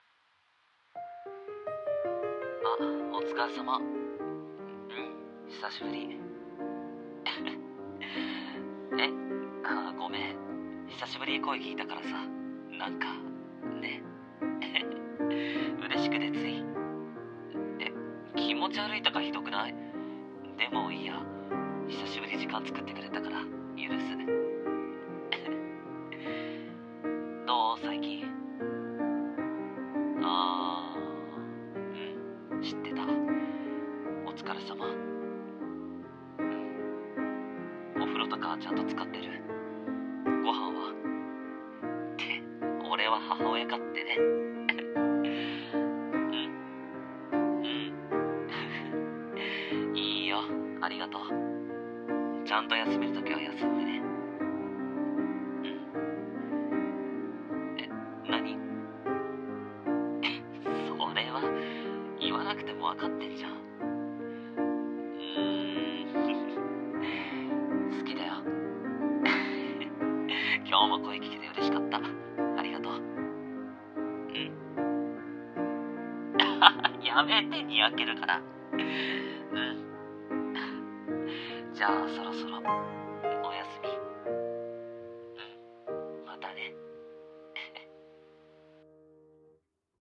【声劇】一人声劇「幸せな電話」